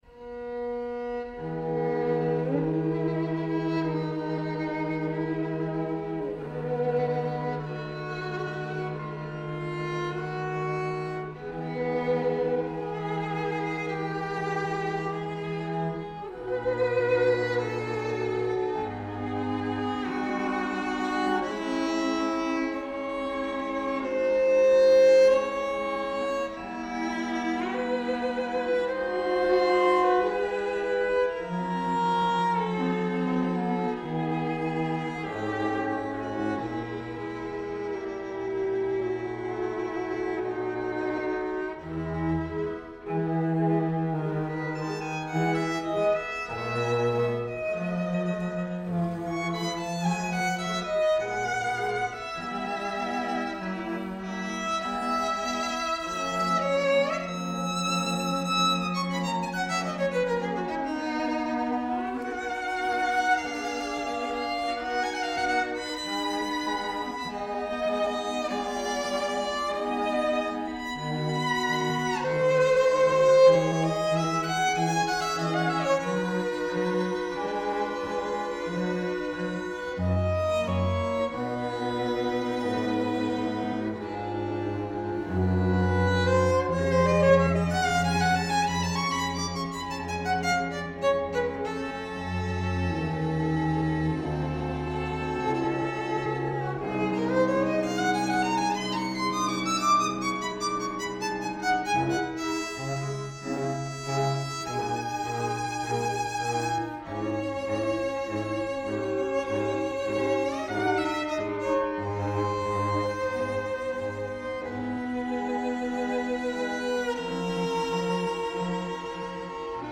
The second movement is a solemn Adagio.